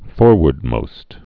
(fôrwərd-mōst)